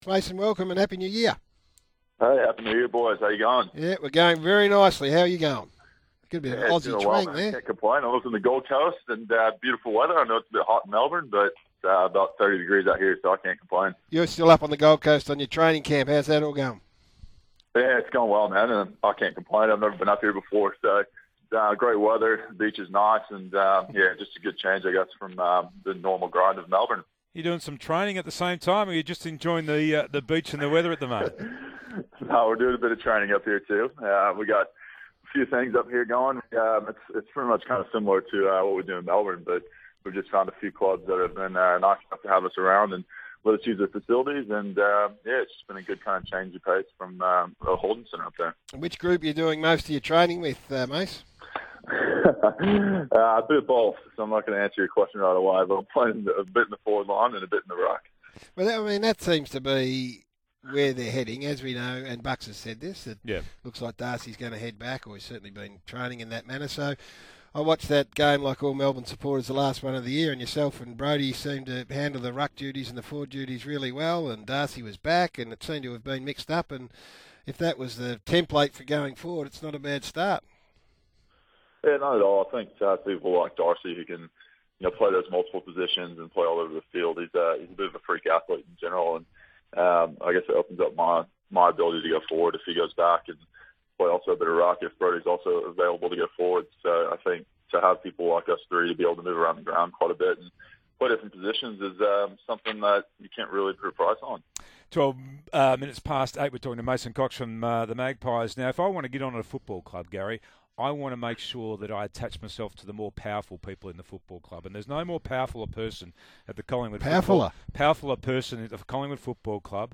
RADIO: Mason Cox on SEN
Listen as Mason Cox joins the SEN Breakfast team from Collingwood's pre-season training camp on the Gold Coast.